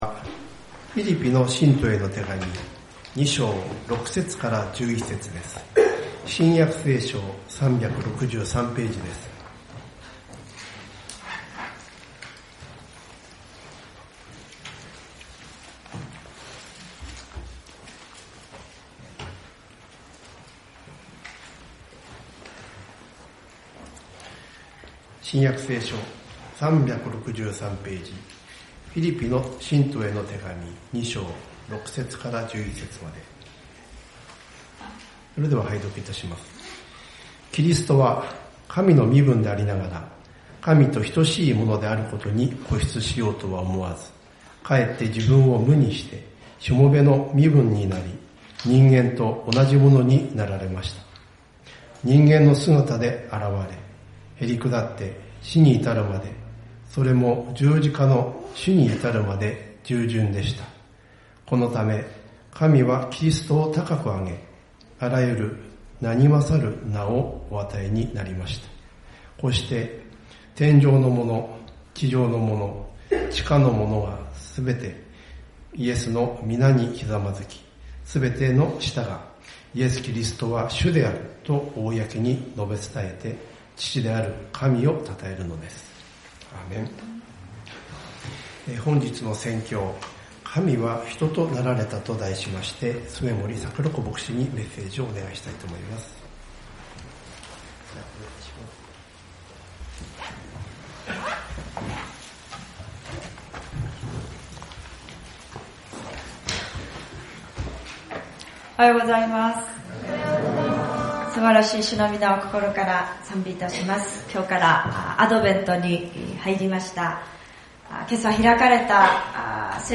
アドベント第一聖日礼拝「神は人となられた」